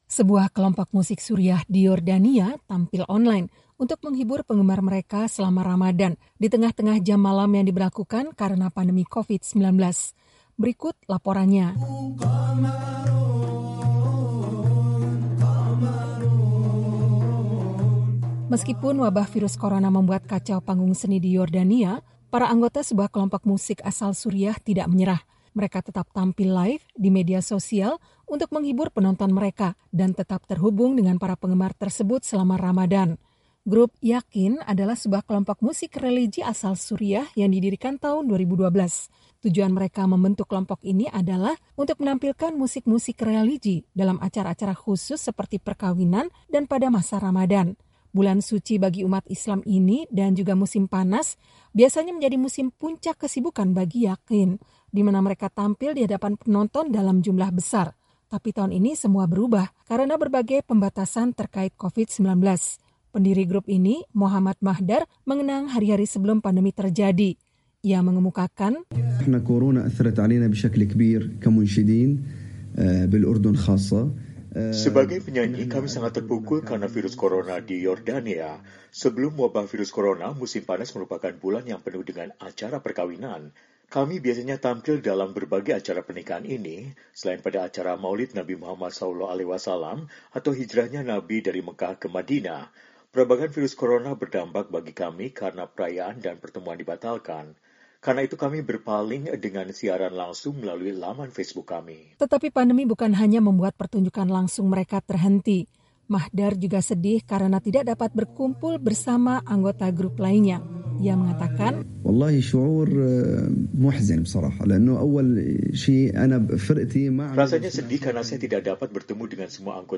Grup Yaqeen, kelompok musik religi asal Suriah, didirikan tahun 2012.